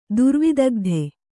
♪ durvidagdhe